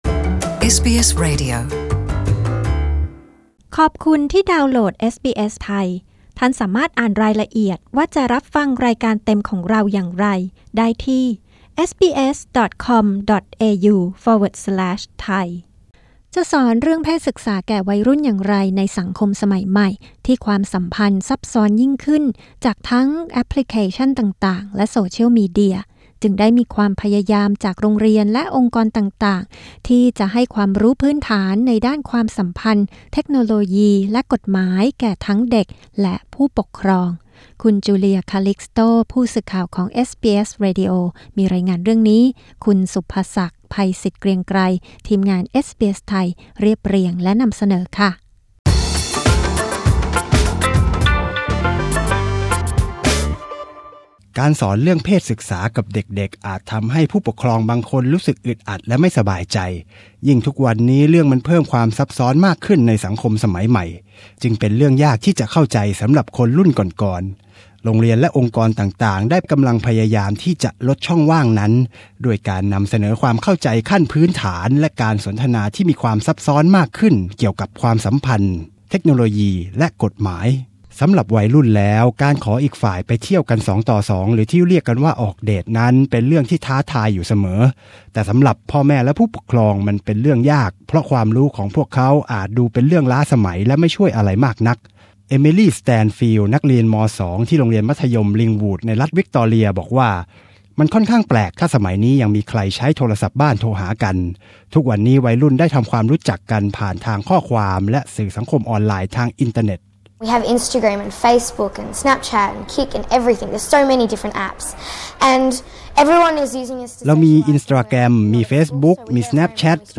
กดปุ่ม 🔊 ด้านบนเพื่อฟังรายงานเรื่องนี้